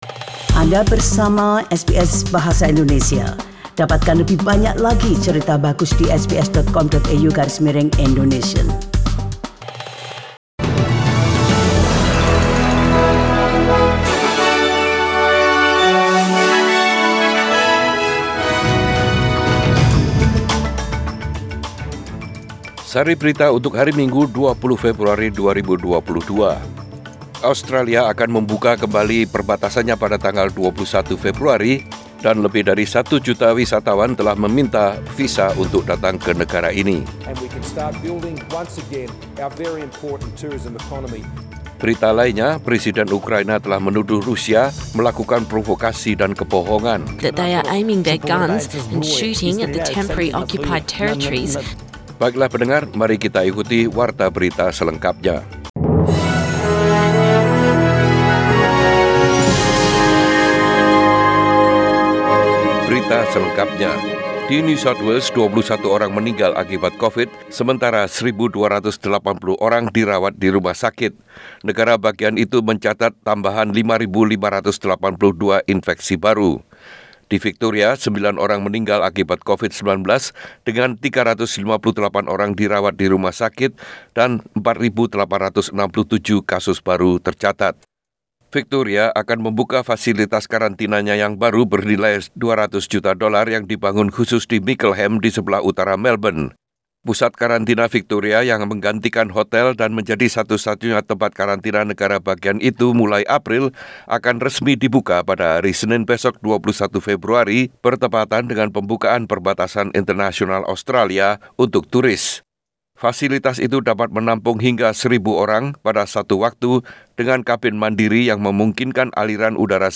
SBS Radio News in Bahasa Indonesia - 20 February 2022
Warta Berita Radio SBS Program Bahasa Indonesia.